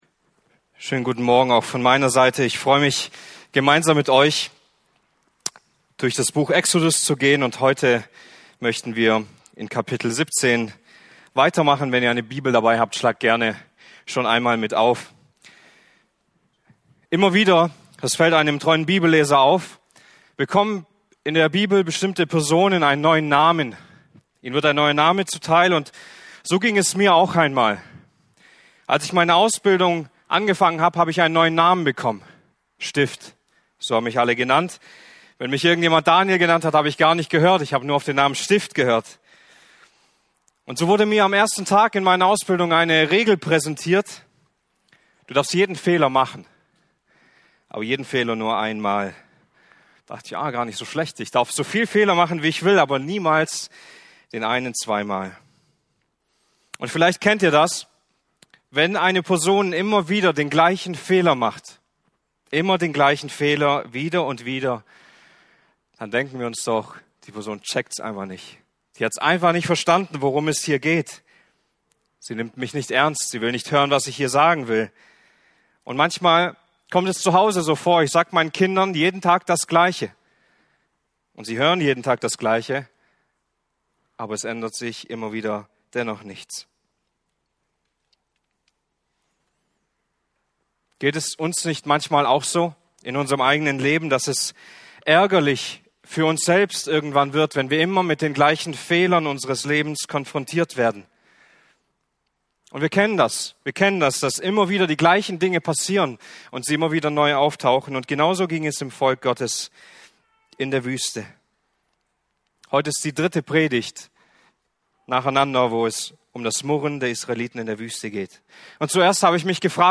Exodus Passage: 2.Mose 17,1-7 Dienstart: Predigten « Der Zorn Gottes